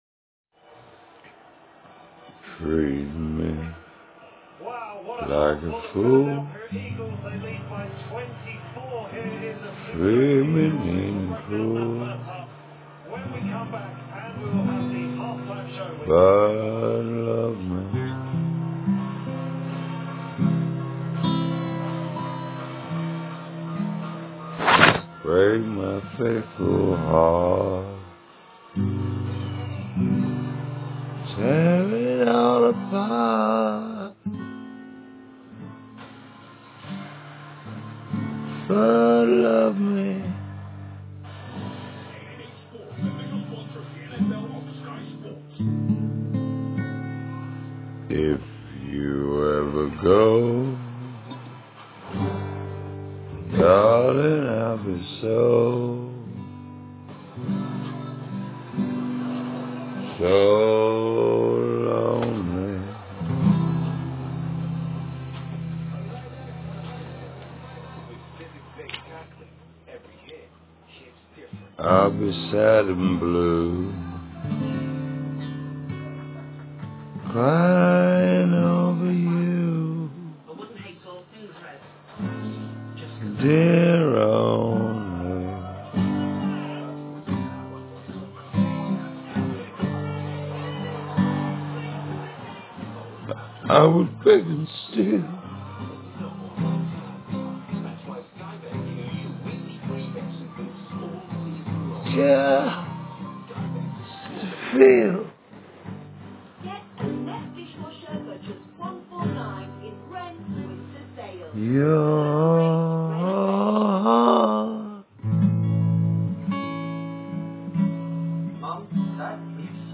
whisperrd at midnight…one take